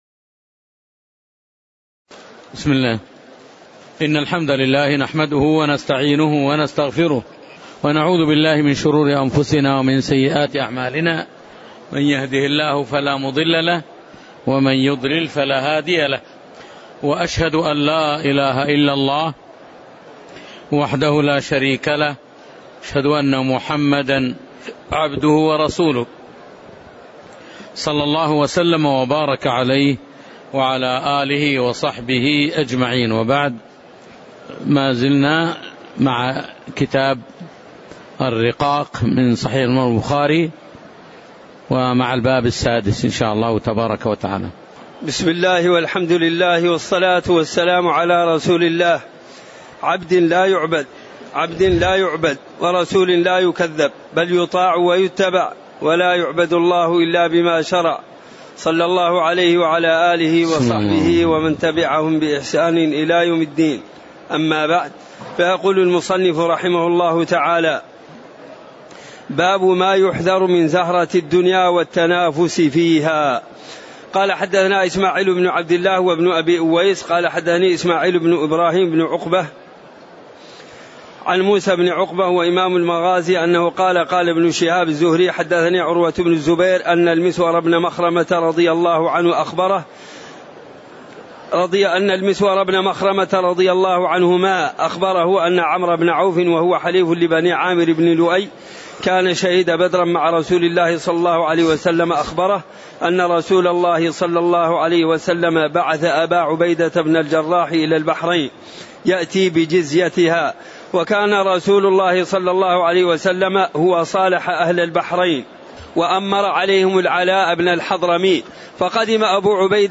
تاريخ النشر ٣ رمضان ١٤٣٩ هـ المكان: المسجد النبوي الشيخ